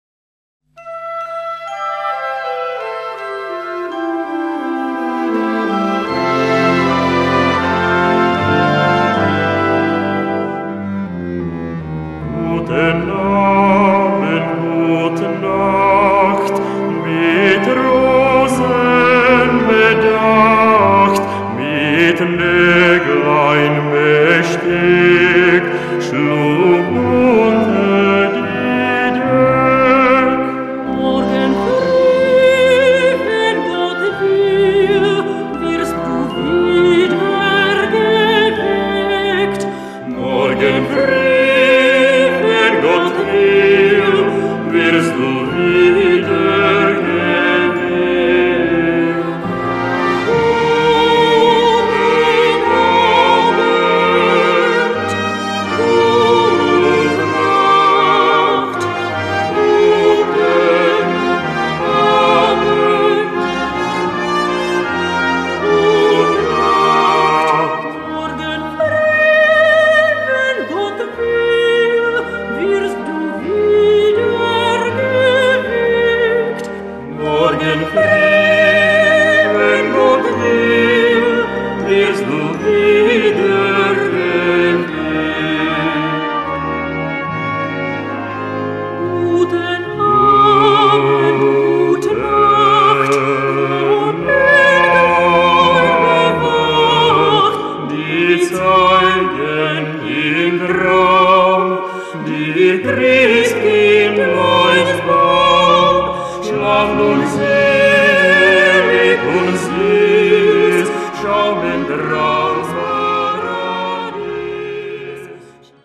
Mezzo-Sopran und Bariton
für Solo Gesang und Blasorchester
Besetzung: Blasorchester